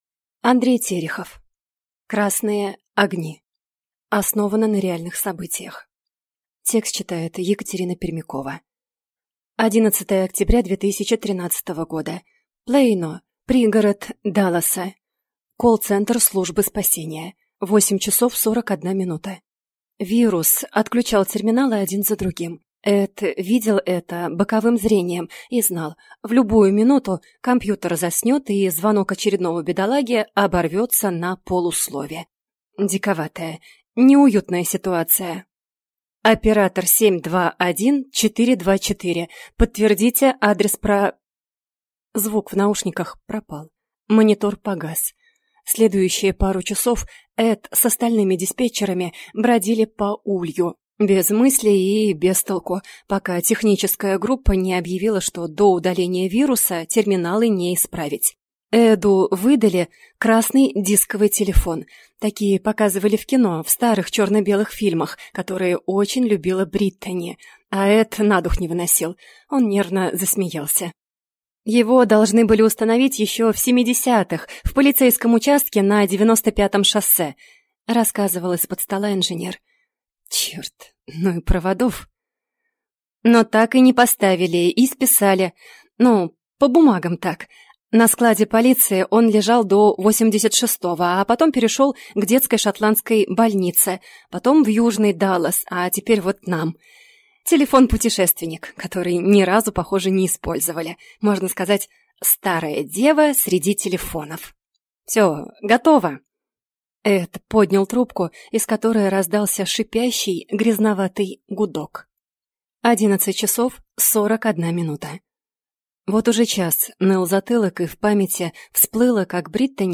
Аудиокнига Красные огни | Библиотека аудиокниг